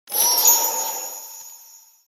Christmas Winter Wind
ChristmasWinterWind.mp3